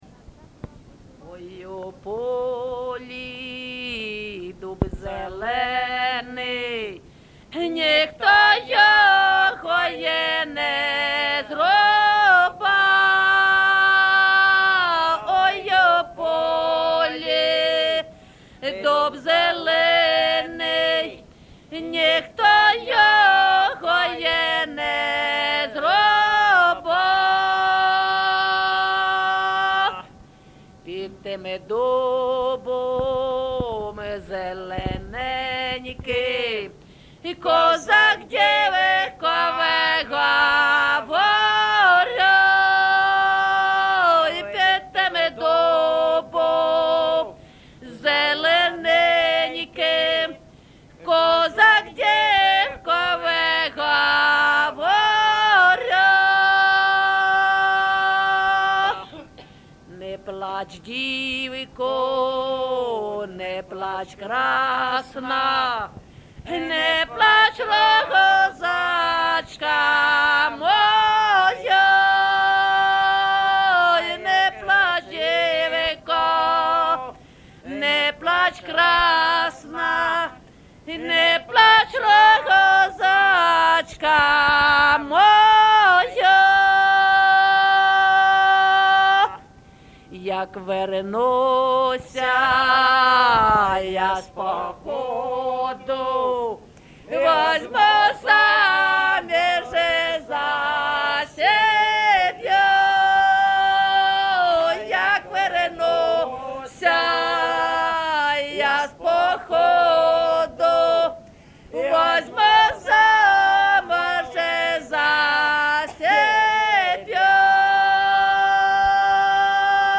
ЖанрПісні з особистого та родинного життя
Місце записус-ще Калинівка, Бахмутський район, Донецька обл., Україна, Слобожанщина